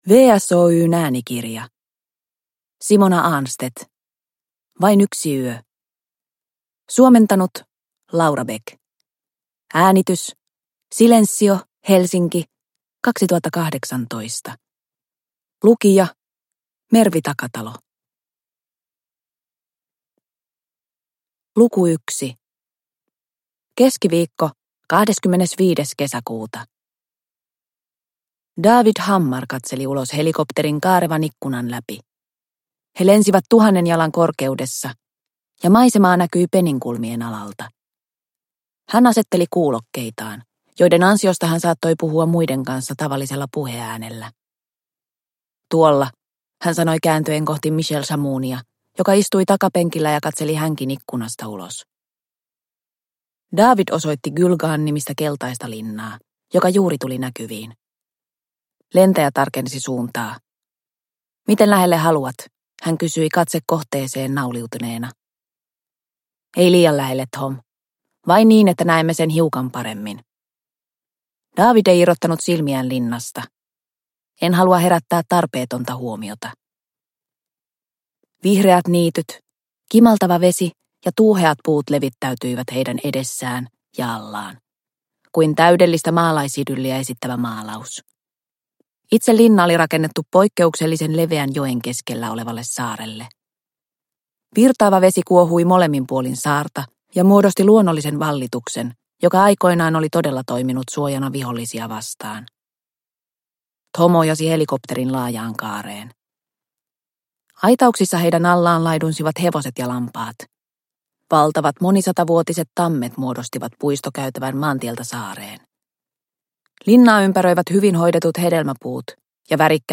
Vain yksi yö – Ljudbok – Laddas ner